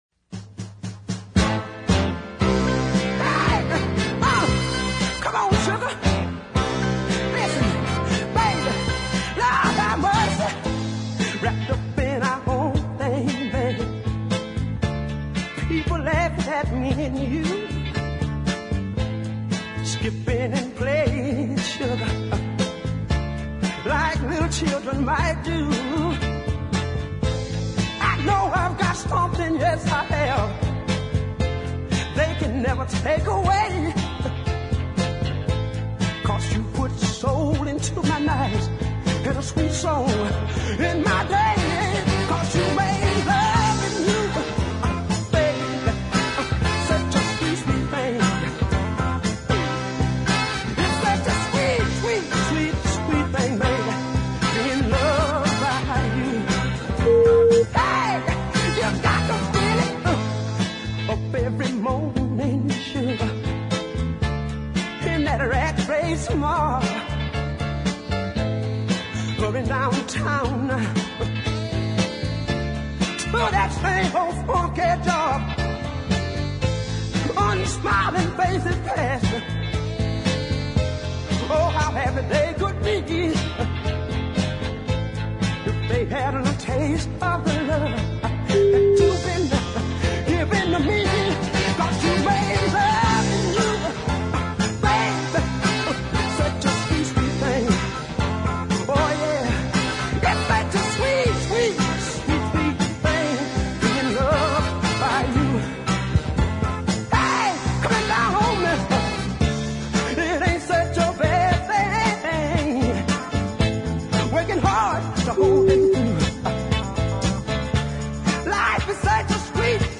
A southern soul cult figure